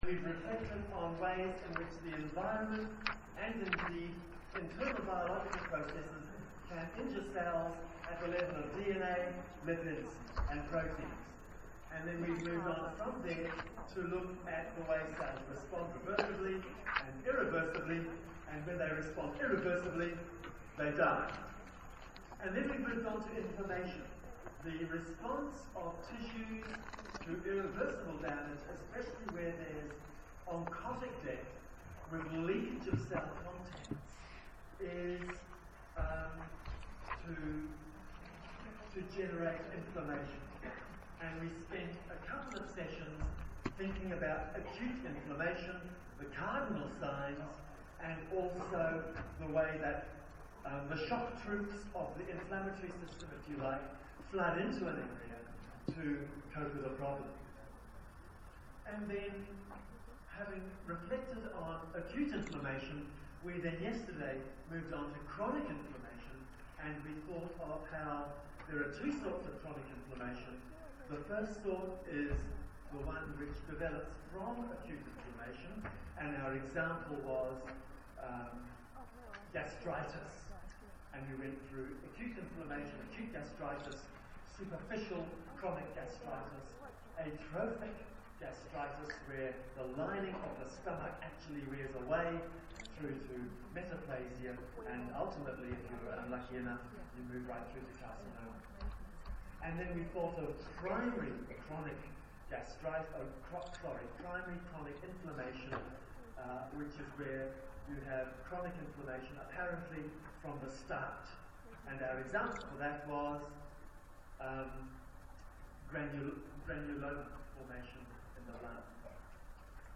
Sound recorder
University lecture recorded using the build-in mic of PMP400 (Bitrate: 32kHz 64kbps, Duration: 5mins, Size: 2.28MB) -